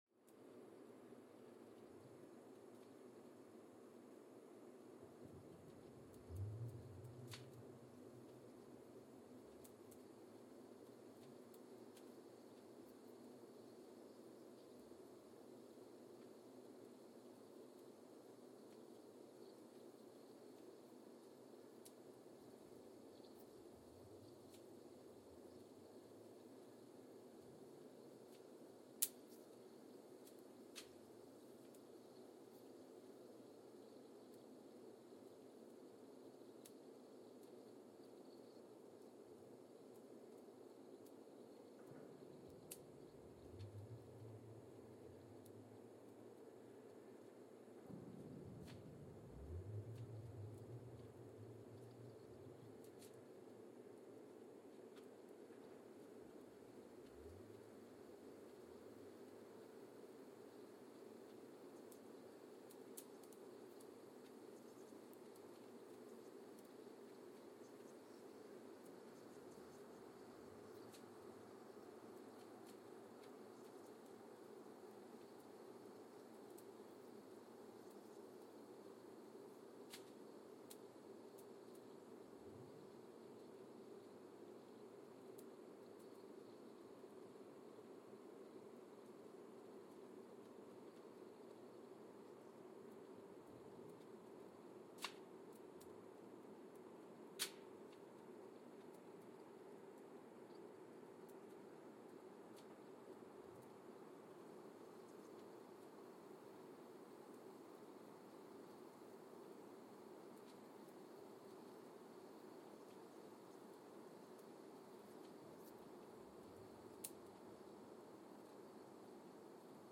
Mbarara, Uganda (seismic) archived on March 6, 2021